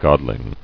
[god·ling]